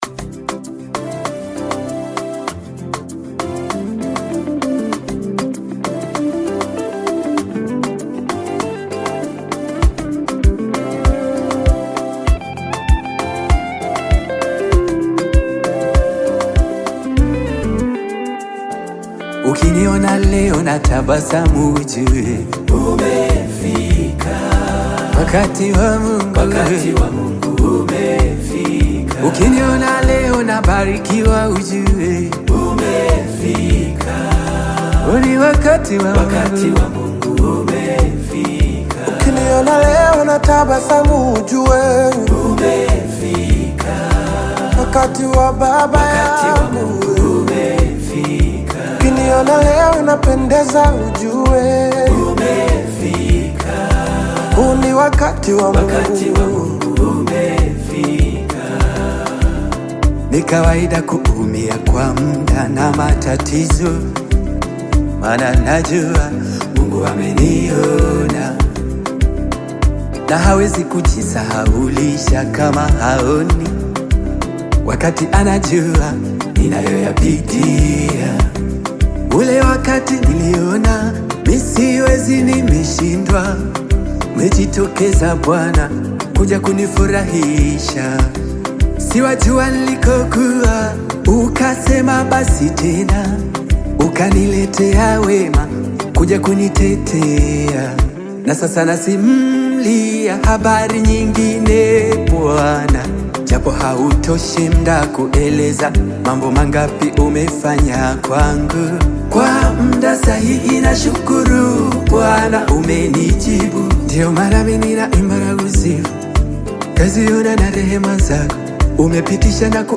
Tanzanian gospel
Gospel song